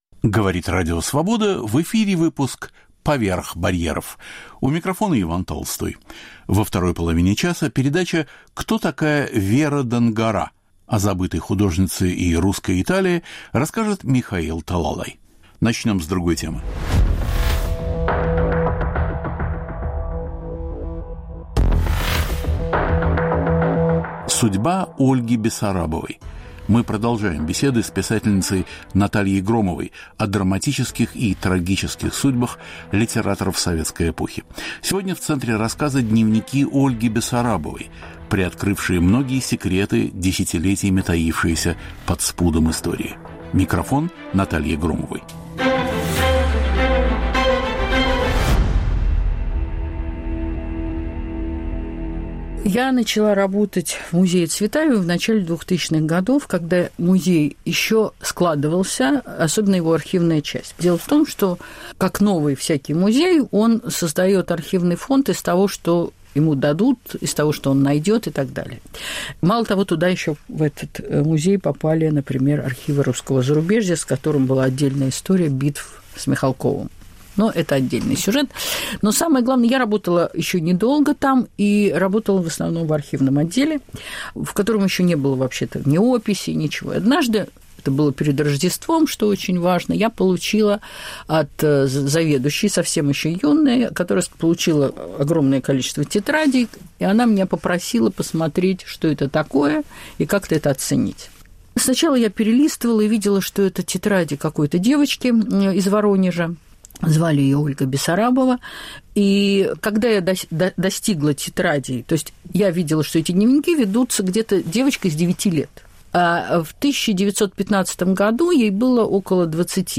Судьба Ольги Бессарабовой. Трагедии советской эпохи в беседах